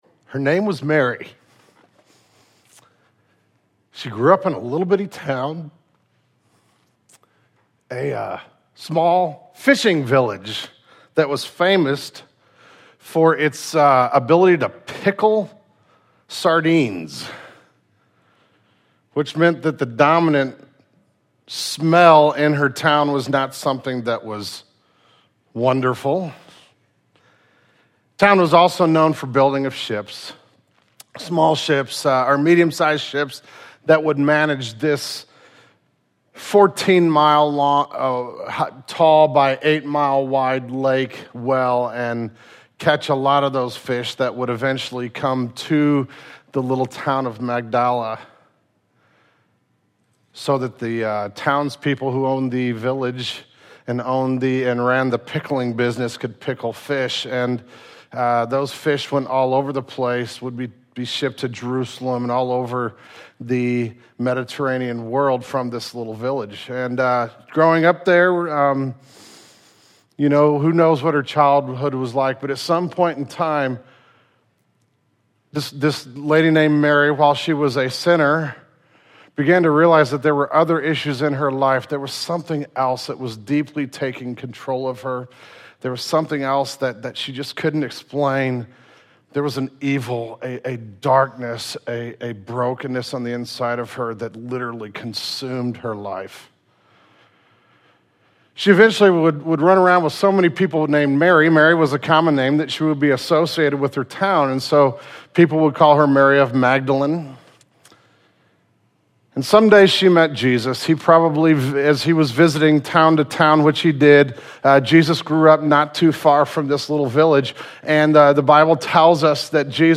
The Sermon Audio archive of Genesis Church - Page 75.